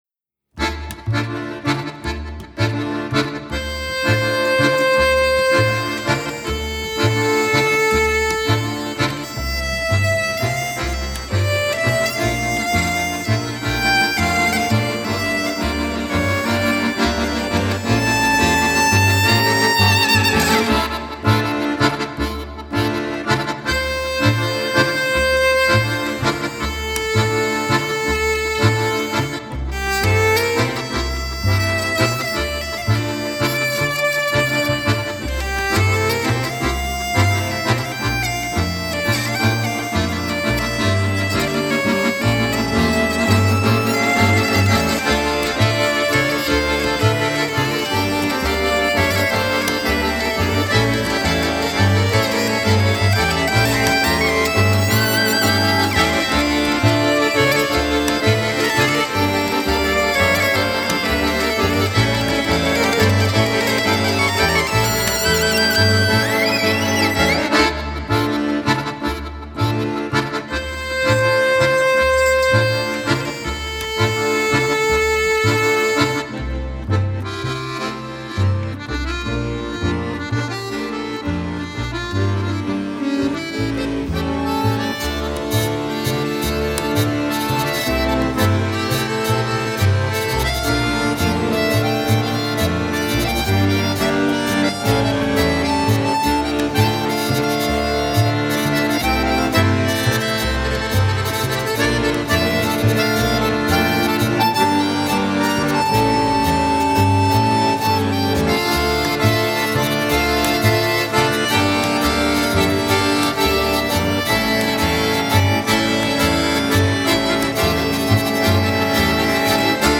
acordeonista